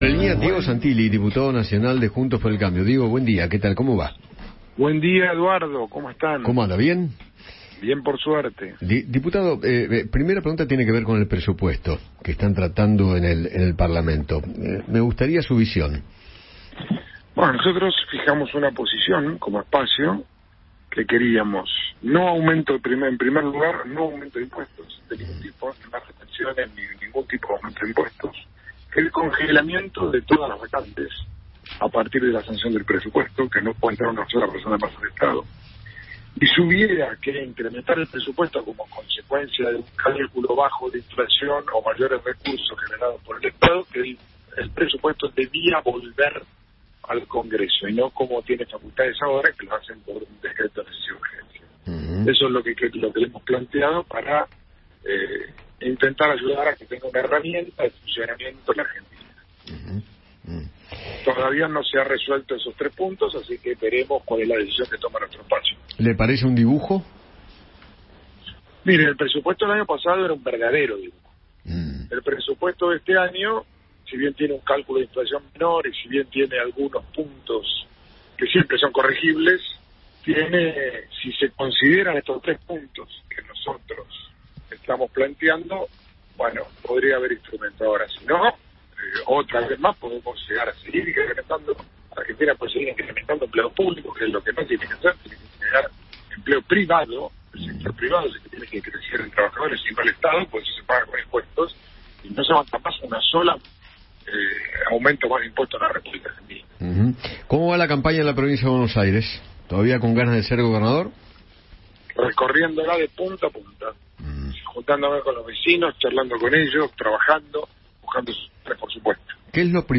Diego Santilli, diputado nacional por Juntos por el Cambio, habló con Eduardo Feinmann sobre los detalles del pedido de la oposición para modificar el Presupuesto 2023.